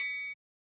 Triangle Zion.wav